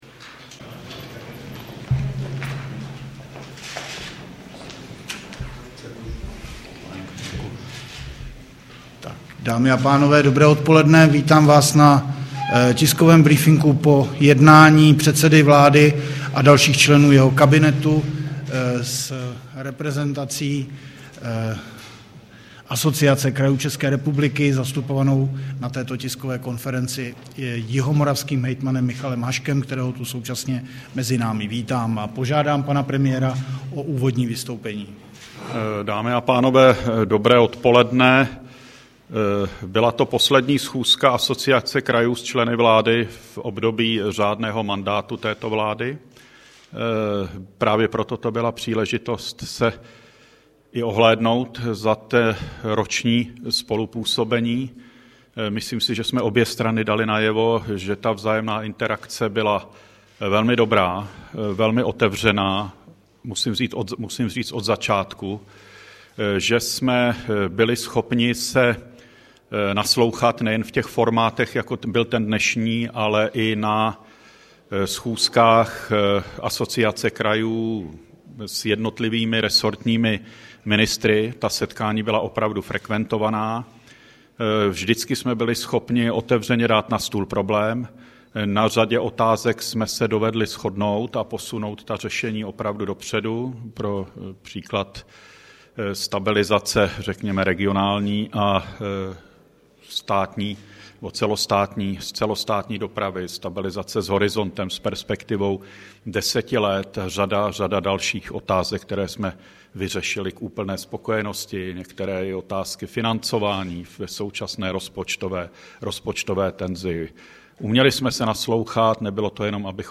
Tiskový brífink po setkání s hejtmany, 21. dubna 2010